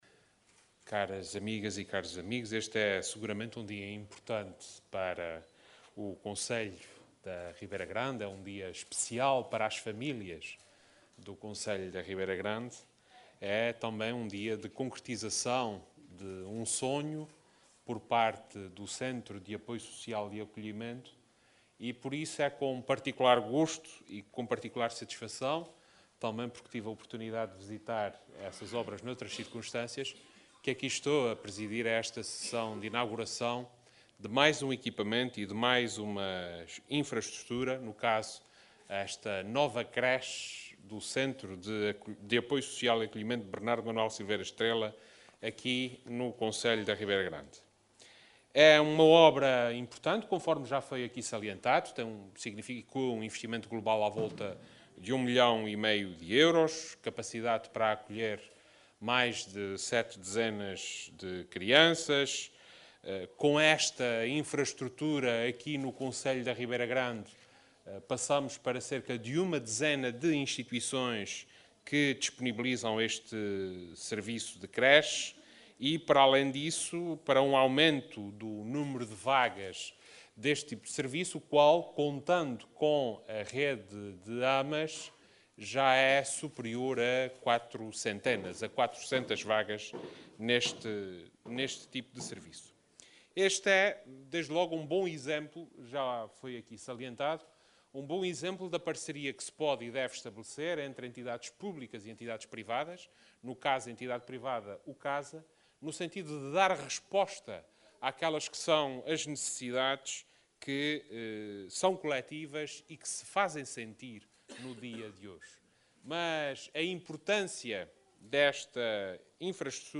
Vasco Cordeiro, que falava na inauguração da creche do Centro de Apoio Social e Acolhimento (CASA) Bernardo da Silveira Estrela, na Ribeira Grande, frisou que esta aposta visa criar as condições para que as famílias açorianas tenham “cada vez melhores condições” para conciliar a sua vida profissional com a sua vida familiar.